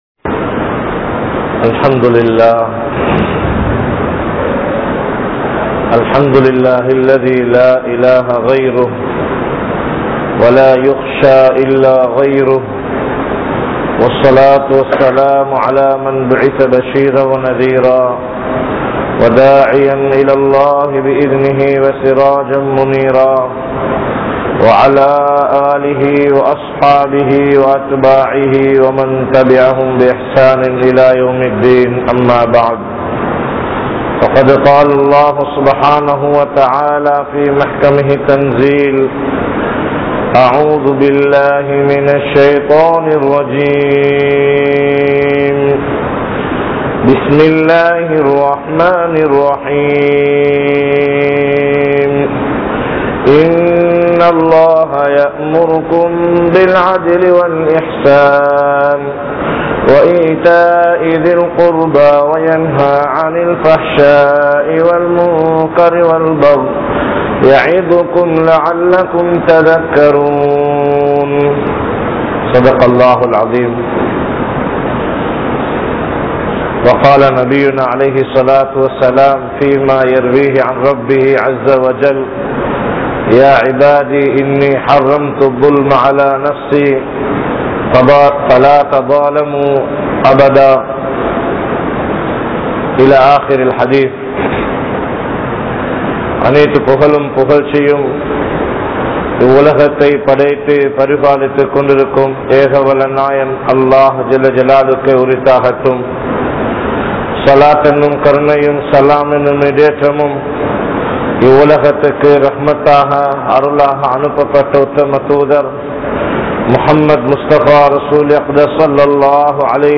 Aniyaayam Seiyaatheerhal (அநியாயம் செய்யாதீர்கள்) | Audio Bayans | All Ceylon Muslim Youth Community | Addalaichenai